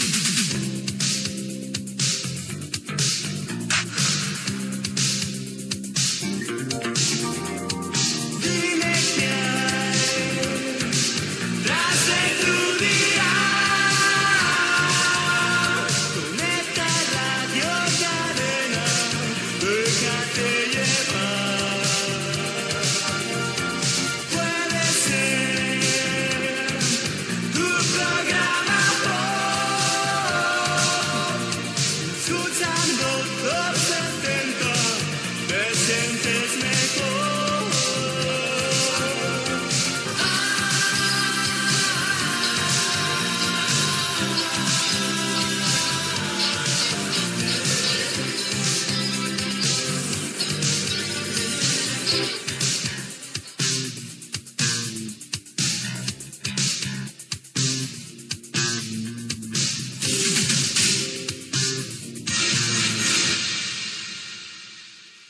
Indicatiu cantat de la cadena
Emissió feta a la banda d'UHF amb el senyal PAL de TVE-1.